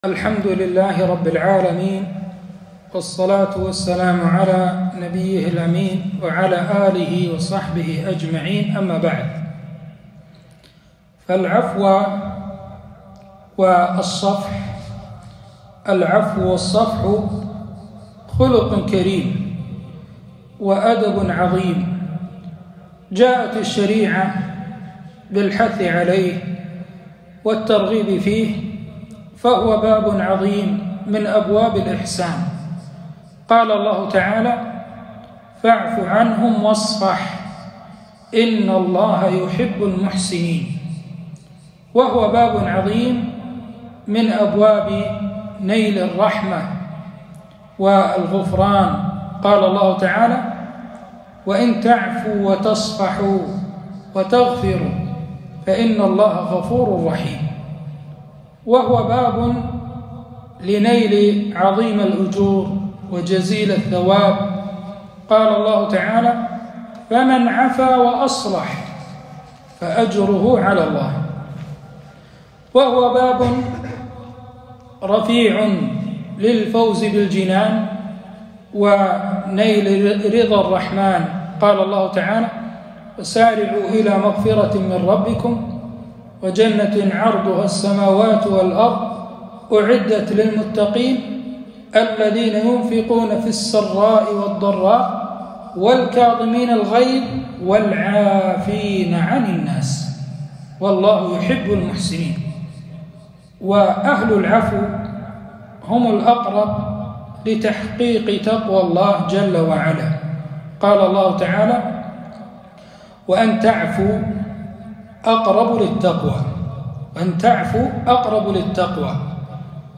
كلمة - فضائل العفو عن الناس